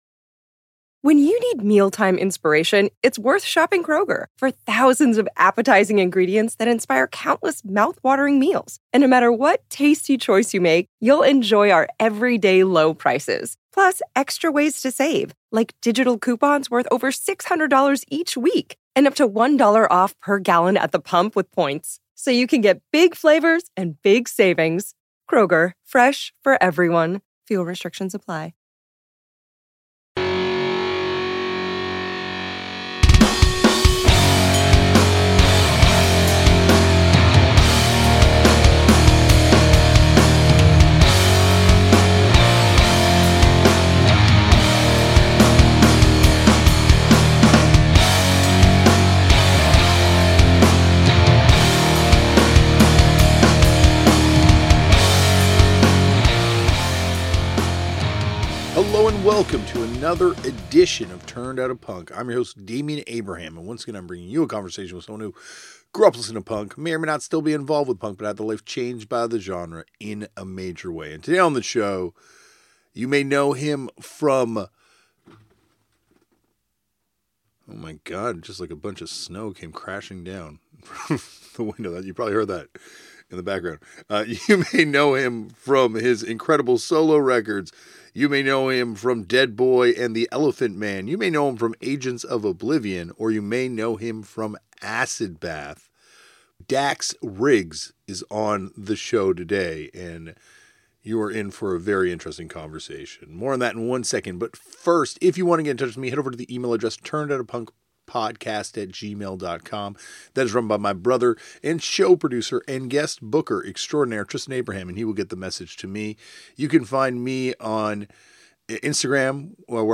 Each week, he sits down and chats with an interesting person from various walks of life to find out how their world was influenced and changed by the discovery of a novelty genre that supposedly died out in 1978... PUNK!